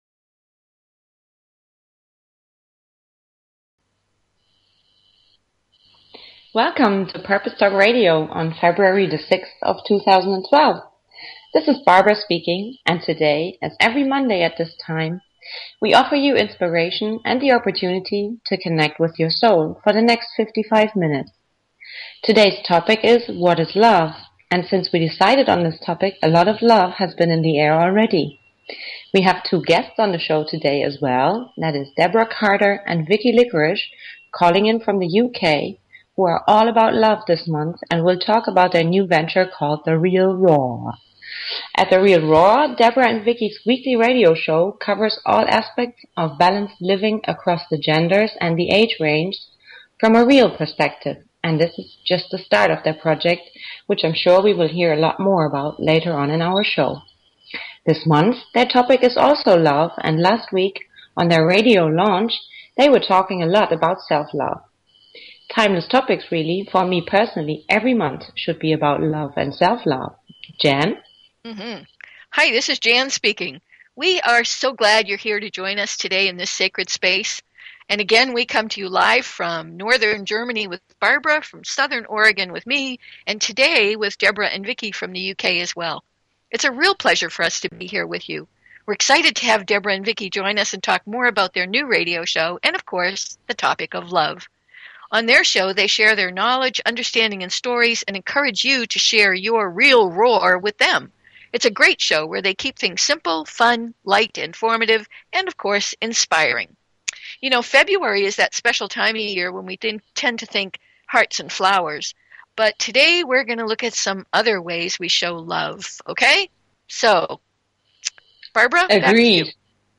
Talk Show Episode, Audio Podcast, Purpose_Talk_Radio and Courtesy of BBS Radio on , show guests , about , categorized as